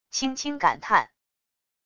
轻轻感叹wav音频